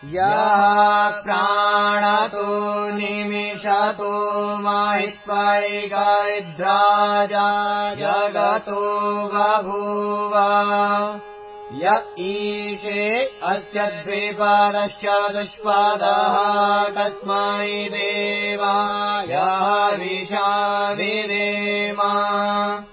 Vedic chanting